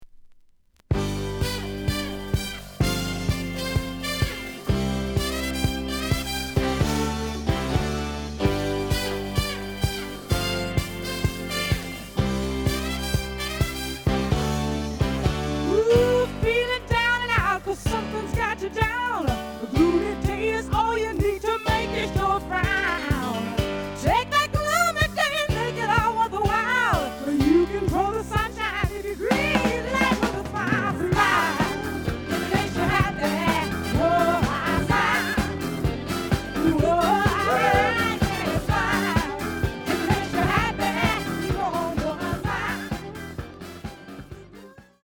The audio sample is recorded from the actual item.
●Genre: Funk, 70's Funk
Edge warp. But doesn't affect playing. Plays good.)